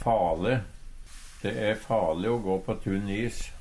DIALEKTORD PÅ NORMERT NORSK fale farleg Ubunde han-/hokj.